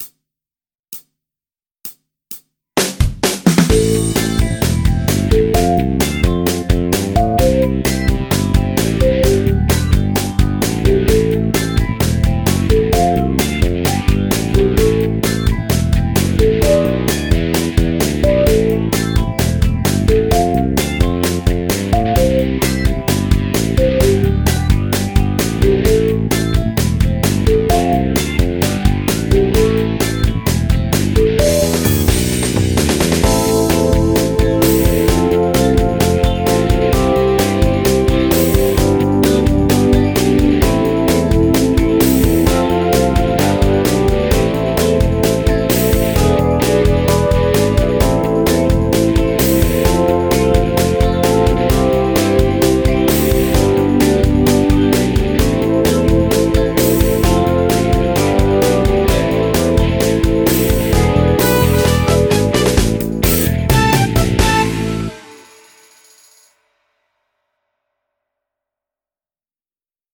ナチュラル・マイナー・スケール ギタースケールハンドブック -島村楽器